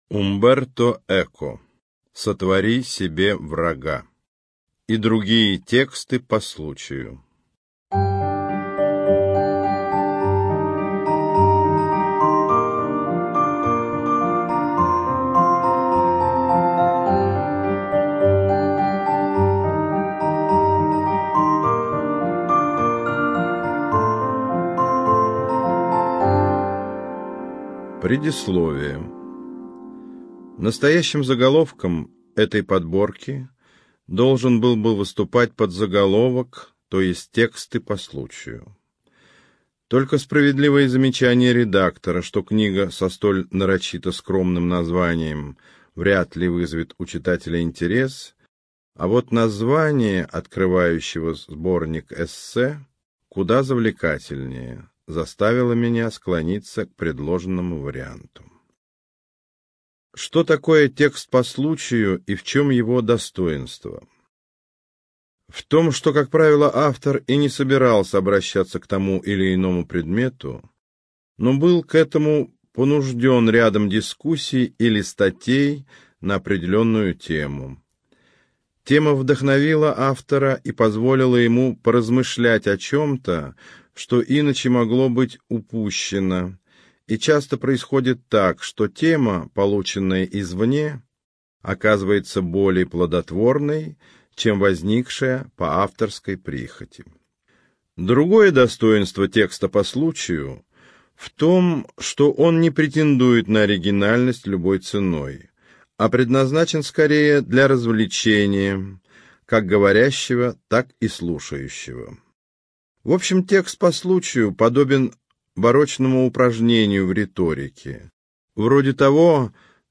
ЖанрПублицистика